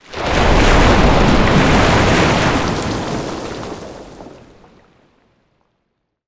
water_spell_wave_crash_02.wav